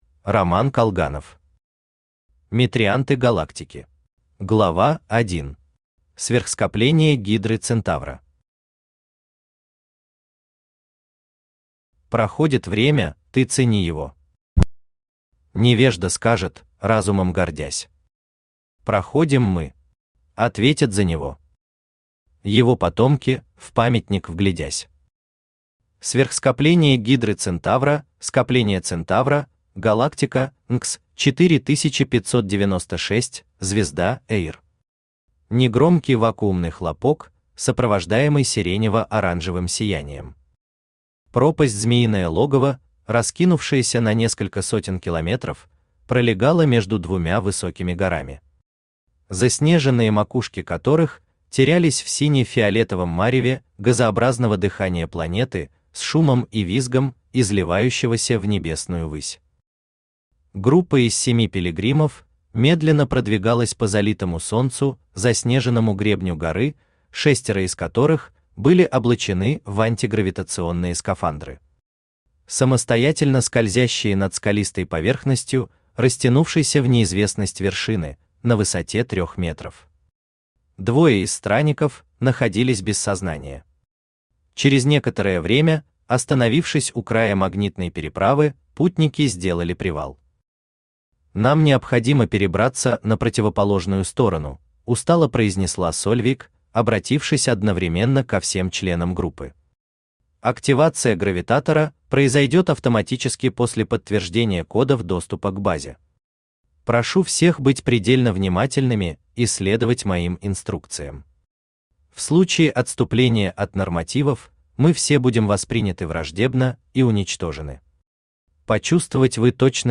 Аудиокнига Метрианты Галактики | Библиотека аудиокниг
Aудиокнига Метрианты Галактики Автор Роман Колганов Читает аудиокнигу Авточтец ЛитРес.